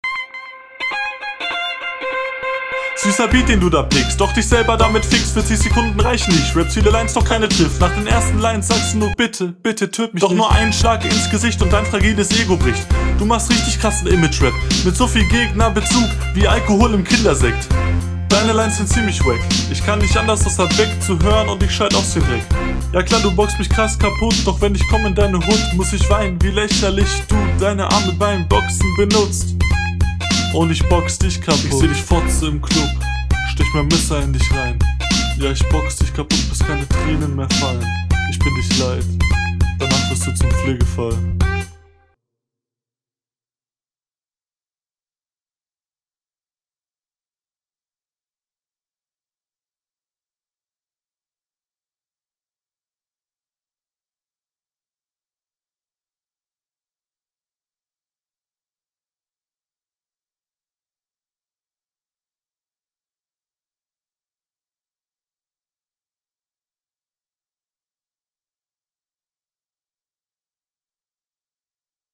hier kannst du leider nicht mithalten kommst nicht so gut auf den beat wie dein …
Soundqualität: gleich wie bei den anderen Flow: gab einen shuffle stimmeinsatz war wie immer aber …